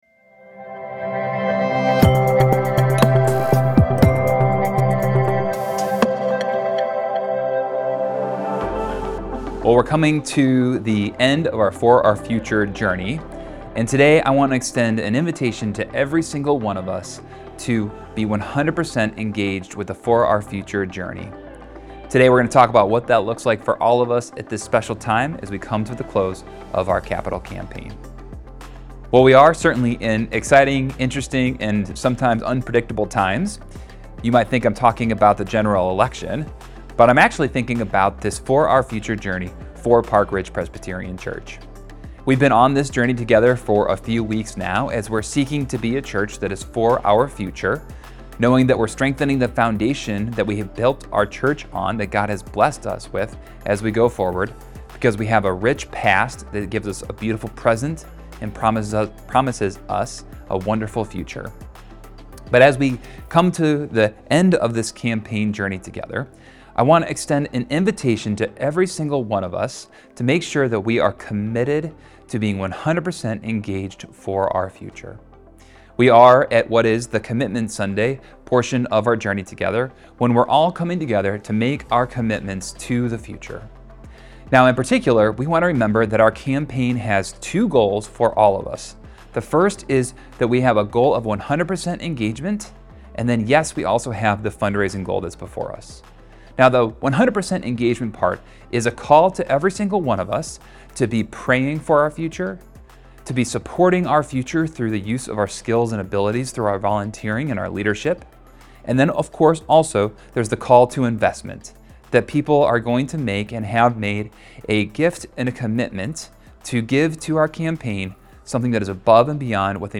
Sunday, November 10, 2024 – 10am Online Service – Week 5 of For Our Future